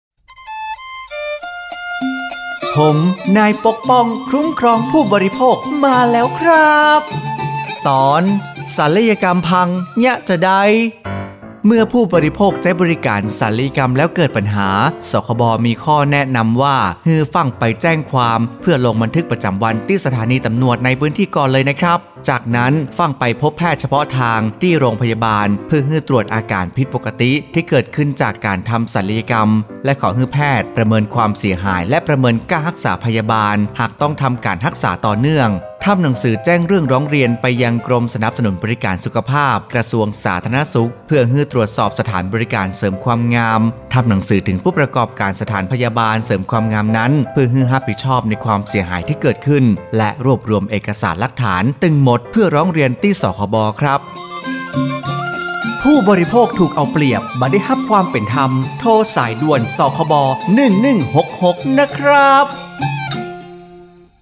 สื่อประชาสัมพันธ์ MP3สปอตวิทยุ ภาคเหนือ
054.สปอตวิทยุ สคบ._ภาคเหนือ_เรื่องที่ 24_.mp3